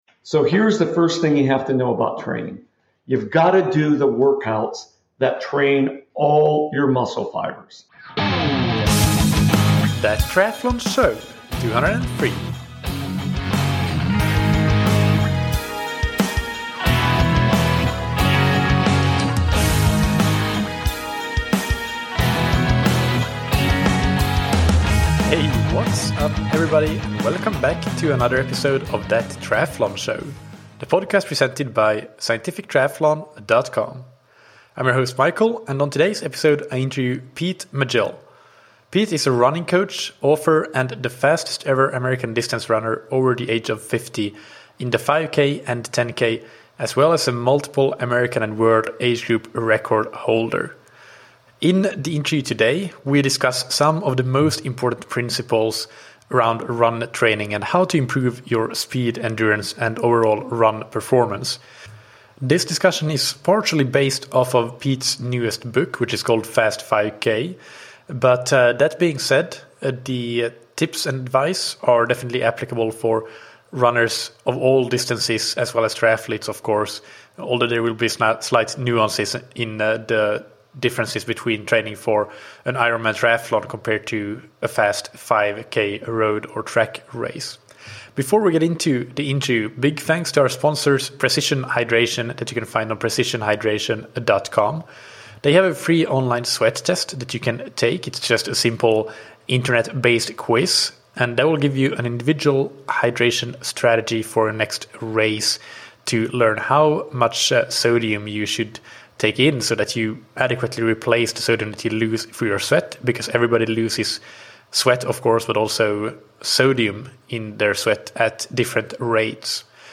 In this interview we discuss the most important principles of improving your running speed, endurance and performance, applicable for everybody from 5k-specialists to Ironman-triathletes.